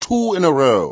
gutterball-3/Gutterball 3/Commentators/Jensen/jen_twoinarow.wav at 608509ccbb5e37c140252d40dfd8be281a70f917